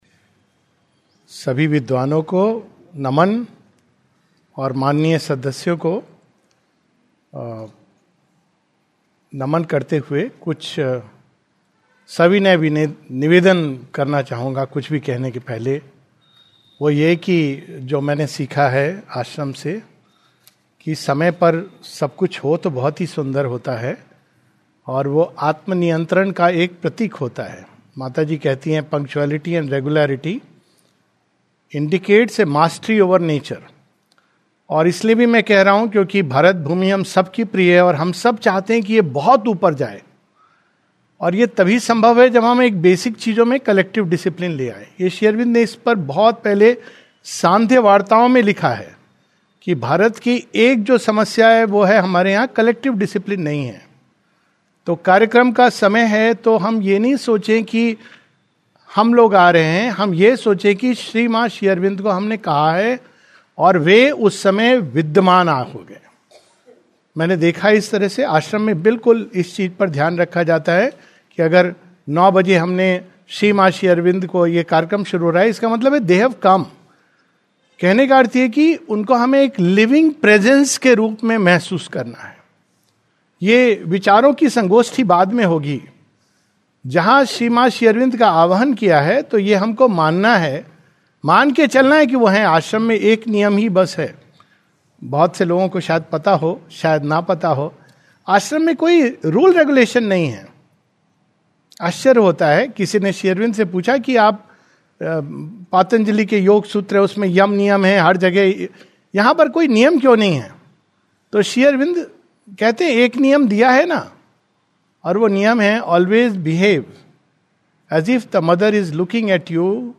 [Evolution of Consciousness and the Supermind] A talk in Hindi